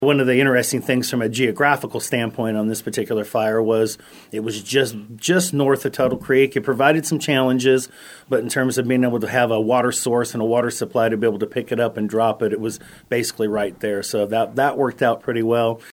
During KMAN’s In Focus Tuesday, Riley County Commission Chair John Ford thanked officials for their hard work over the past few days.